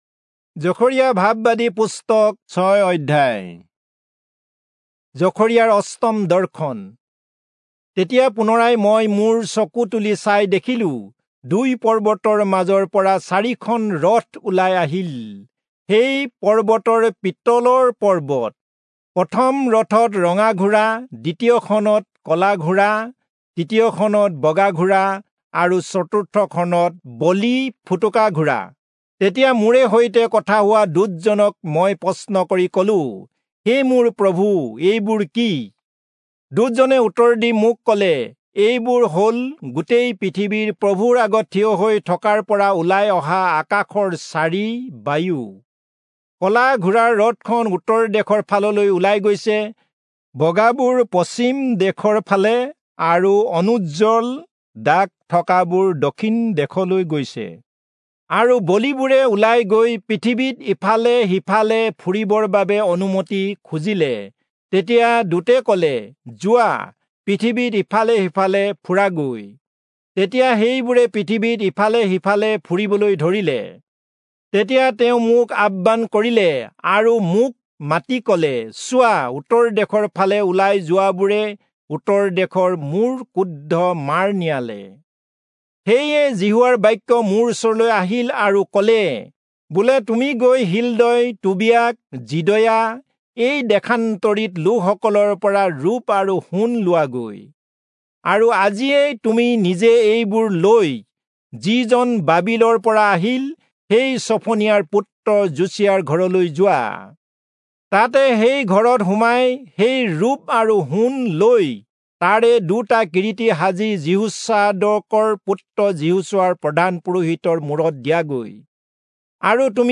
Assamese Audio Bible - Zechariah 4 in Irvas bible version